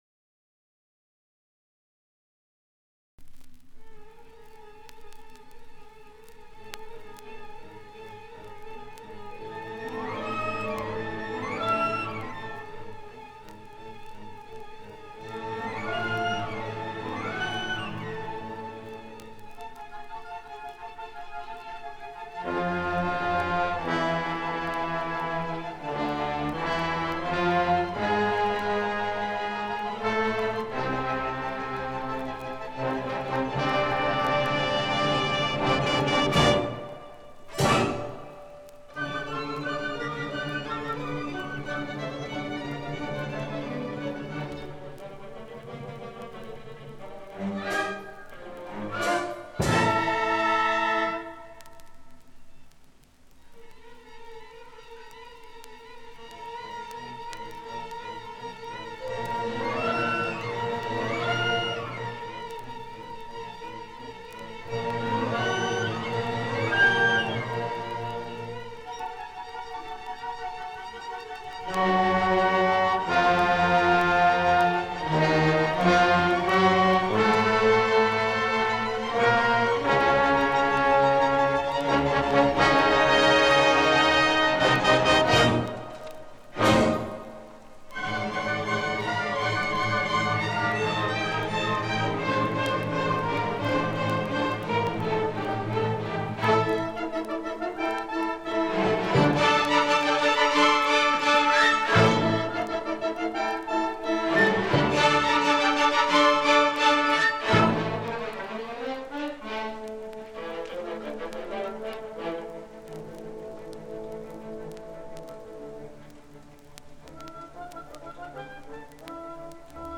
4ced12c751cda3ac466e1dc4ec87aa05240a4ca1.mp3 Title 1967 Music in May recording Description An audio recording of the 1967 Music in May performance at Pacific University.
It brings outstanding high school music students together on the university campus for several days of lessons and events, culminating in the final concert that this recording preserves.